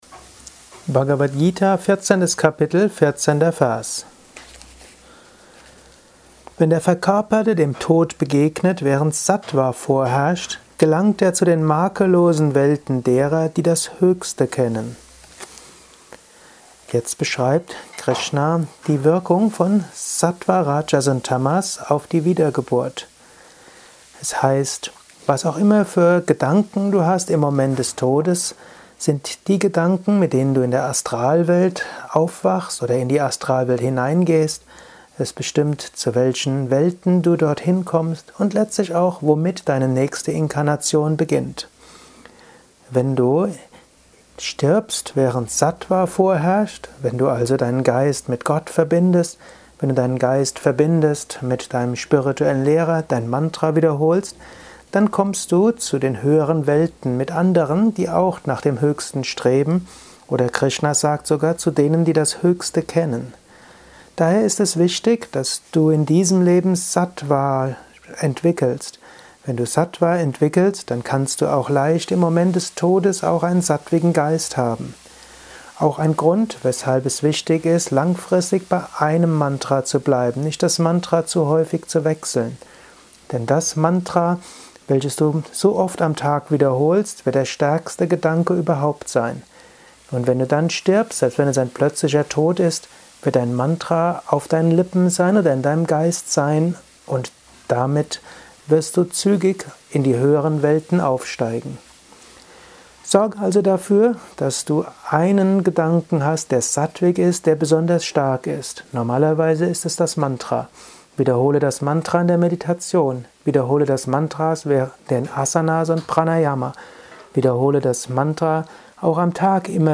Dies ist ein kurzer Kommentar als Inspiration für